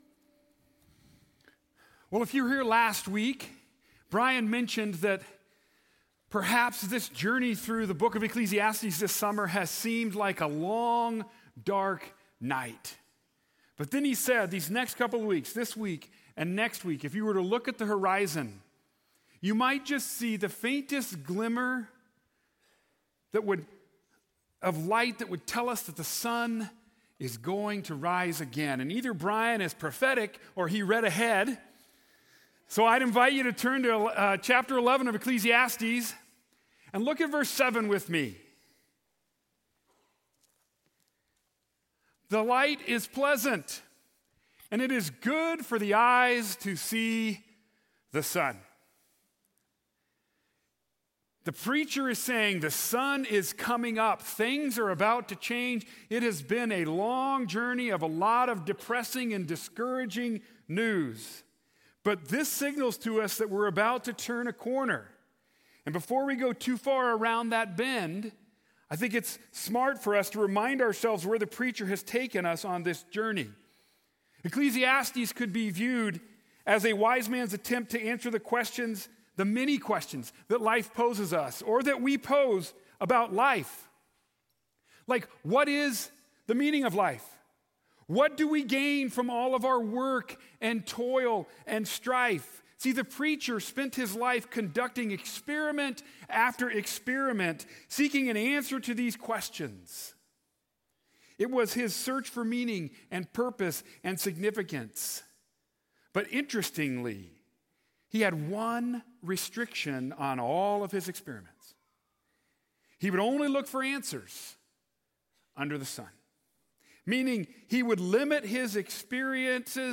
Sermon: Rejoice and Remember